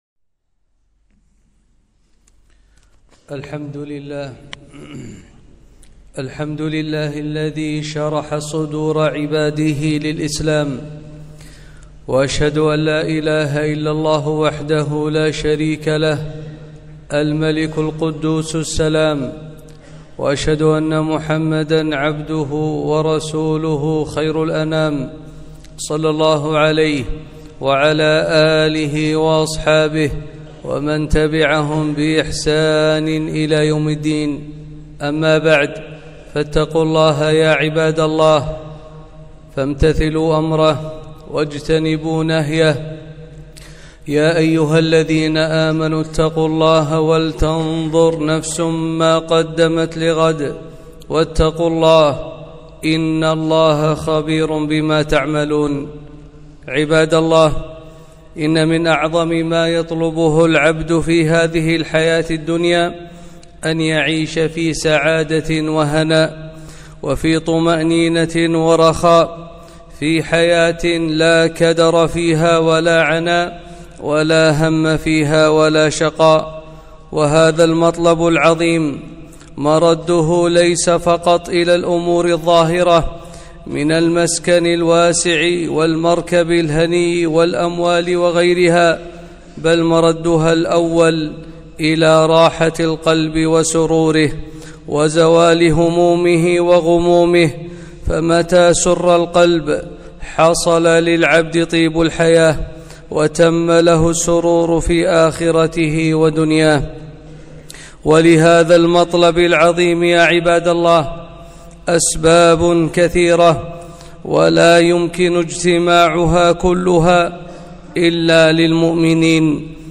خطبة - طيب الحياة